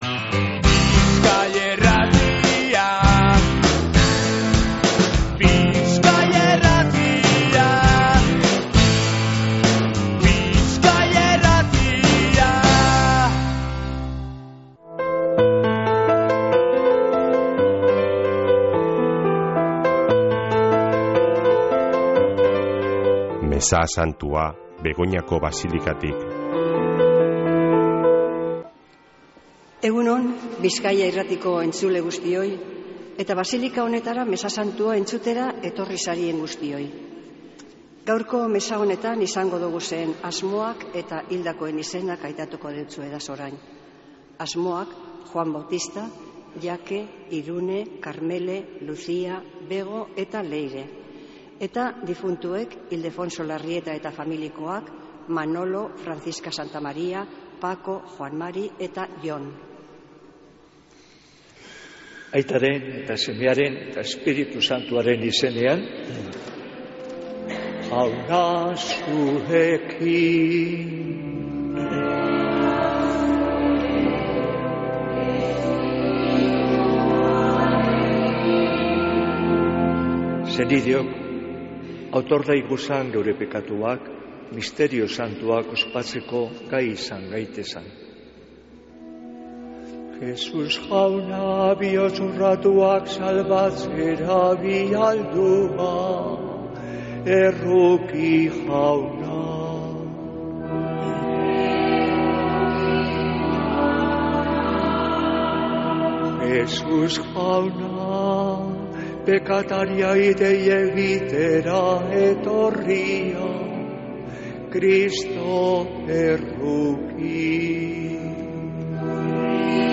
Mezea Begoñako Basilikatik | Bizkaia Irratia
Mezea (24-11-26)